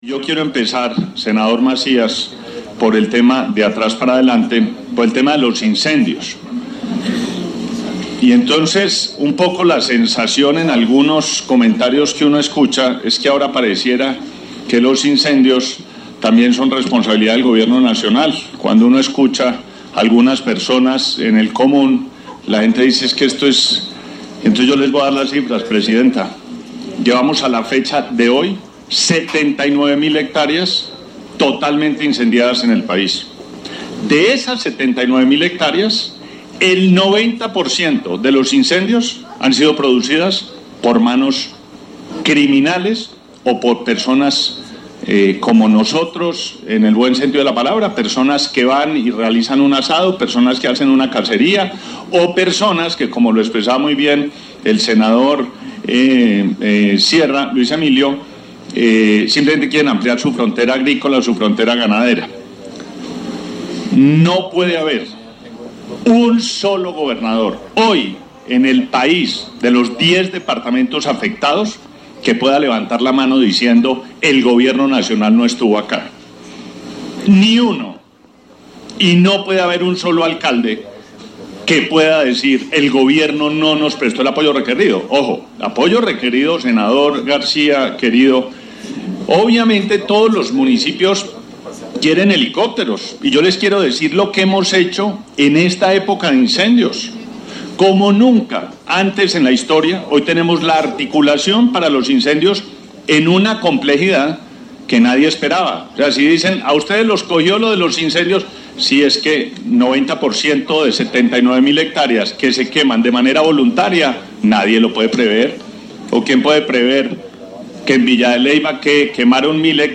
Al asistir al debate que sobre Sequía realizó la Comisión Quinta del Senado de la República, el Ministro Vallejo dijo que desde diciembre del año pasado “ha recorrido, junto con el Director del IDEAMIDEAM Instituto de Hidrología, Meteorología y Estudios Ambientales, por lo menos tres veces el país e iniciamos desde hace un mes otra vuelta, sosteniendo reuniones con gobernadores, alcaldes, autoridades ambientales y del riesgo, hablando sobre las estrategias de prevención y advirtiendo la presencia del Fenómeno de El Niño en el país y sobre las consecuencias que puede generar”.
Declaraciones del Ministro de Ambiente y Desarrollo Sostenible, Gabriel Vallejo López